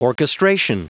Prononciation du mot orchestration en anglais (fichier audio)
Prononciation du mot : orchestration